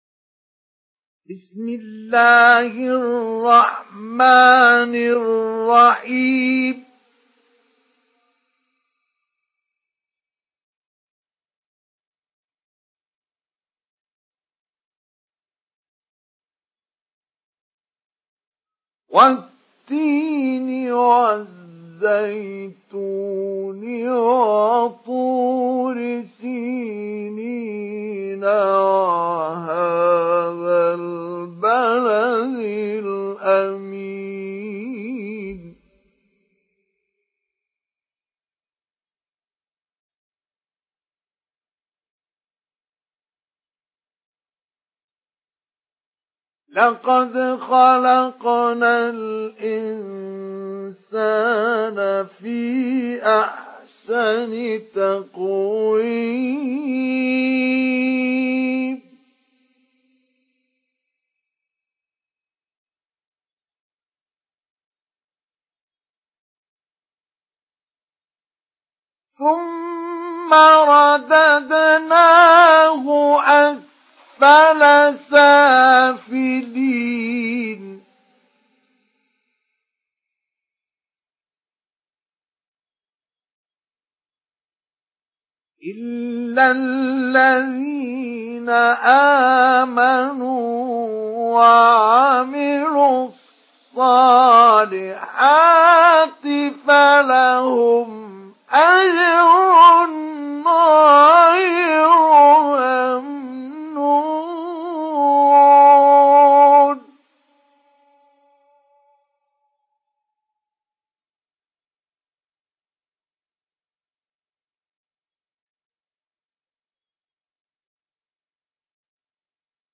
Surah At-Tin MP3 in the Voice of Mustafa Ismail Mujawwad in Hafs Narration
Listen and download the full recitation in MP3 format via direct and fast links in multiple qualities to your mobile phone.